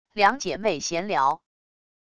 两姐妹闲聊wav音频